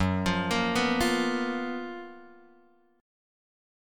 F# Major 11th